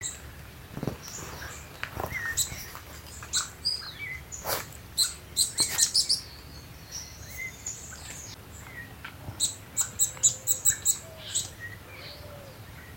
Scientific name: Basileuterus culicivorus auricapilla
English Name: Golden-crowned Warbler
Location or protected area: Parque Nacional El Palmar
Condition: Wild
Certainty: Observed, Recorded vocal